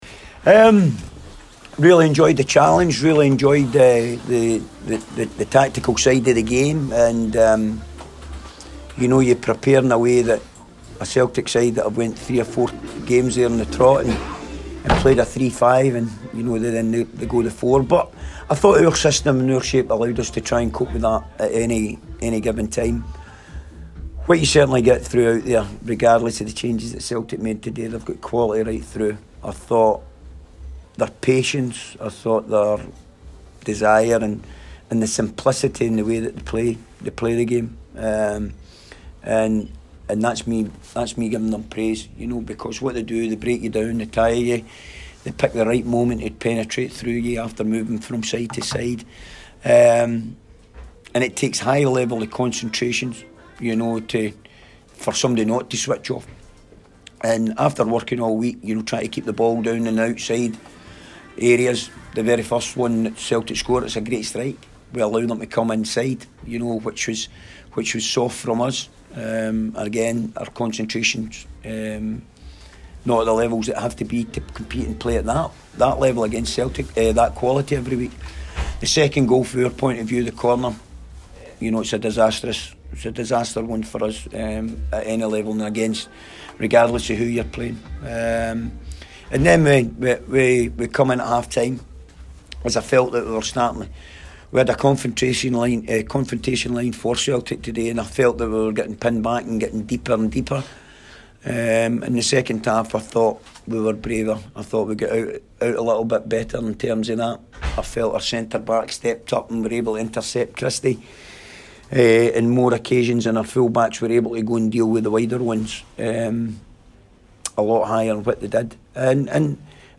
press conference after the William Hill Scottish Cup match.